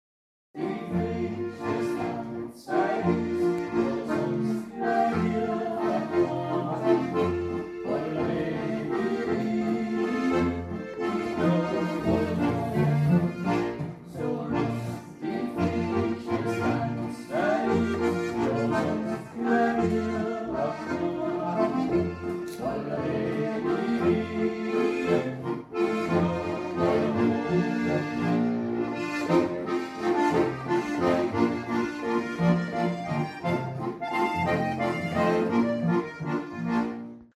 Musik im Garten vom Seniorenheim Rainbach
Das Wetter spielte mit, und so konnten wir im Freien spielen; und es wurde auch gesungen.
Gesangseinlagen.